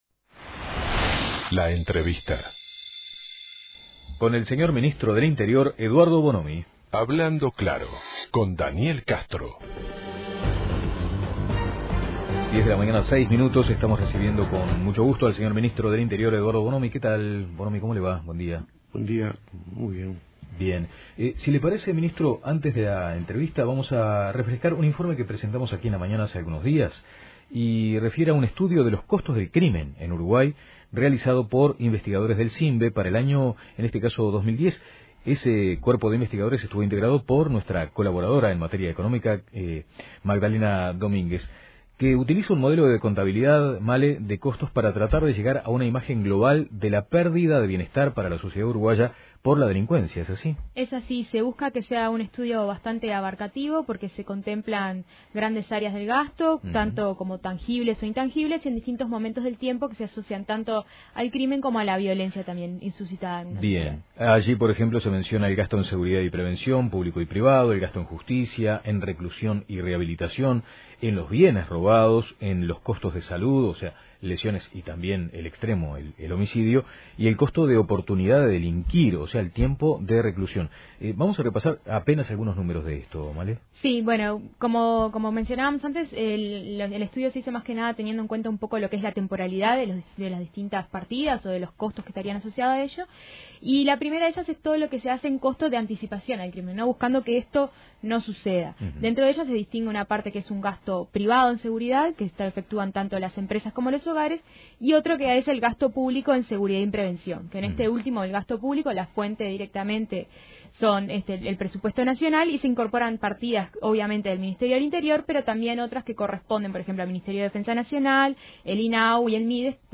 El ministro del Interior, Eduardo Bonomi, dijo a El Espectador que están seguros que los responsables de la muerte de un bebé y su tío en Casabó, y de una pareja de paraguayos en Giannattasio en el mes de febrero son las mismas personas. Bonomi explicó que no pueden avanzar en la investigación por problemas en el sistema judicial.